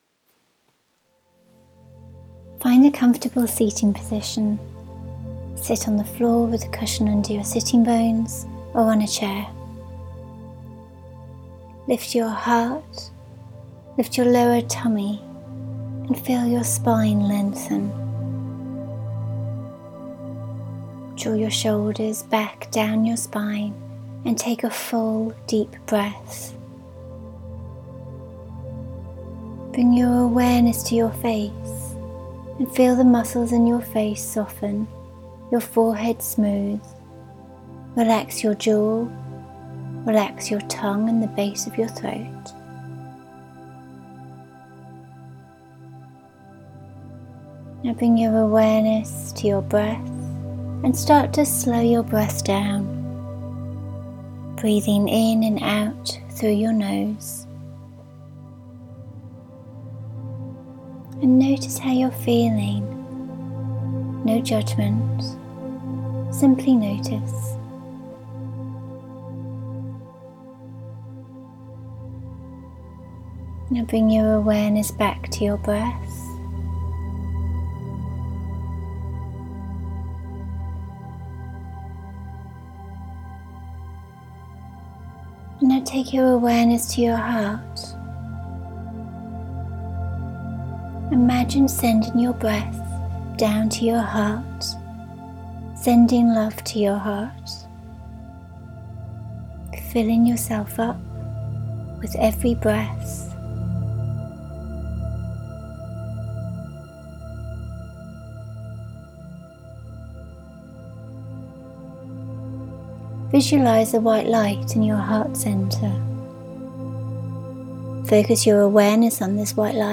Here is a short guided meditation I recorded for my tribe that sign up to my newsletter.
Breathe-love-into-life-meditation-Fin-1.mp3